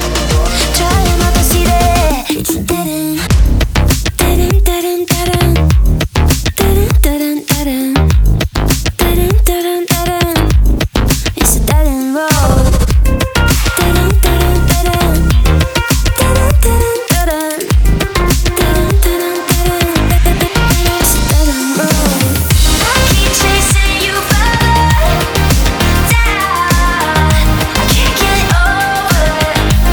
• Electronic